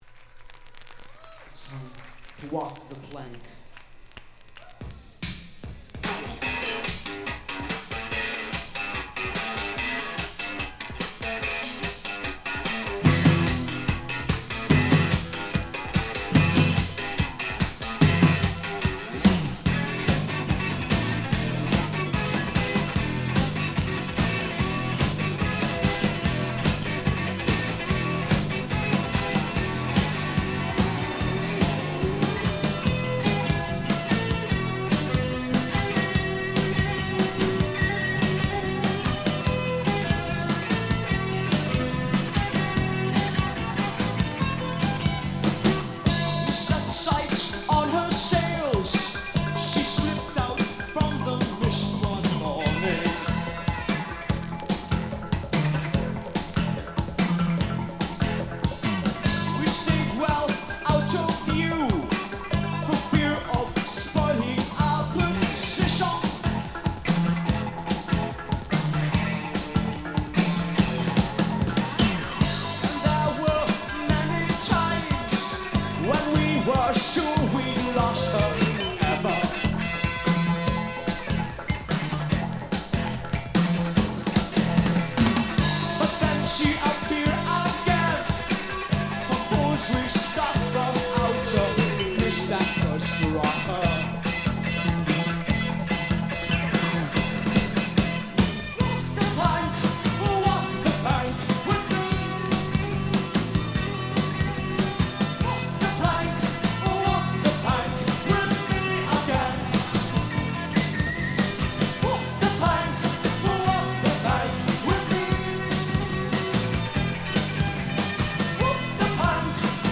Recorded at Barrymores, Ottawa, October 26, 1982